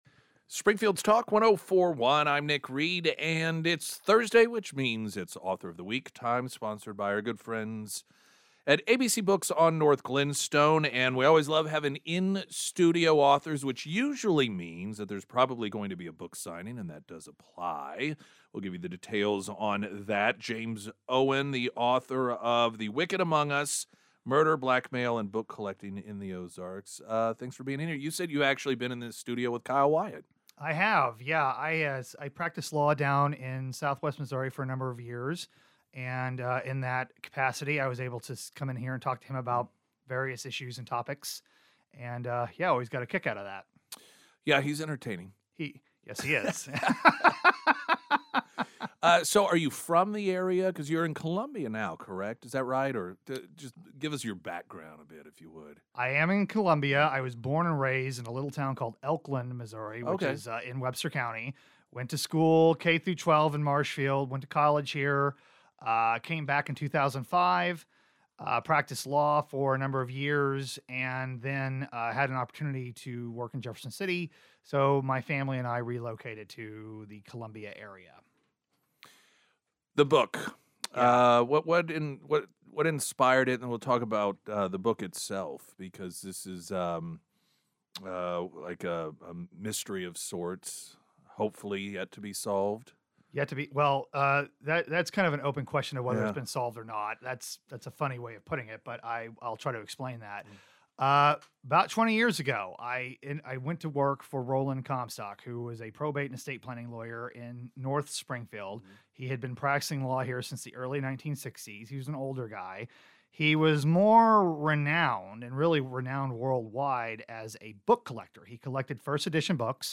interview was broadcast on KSGF Mornings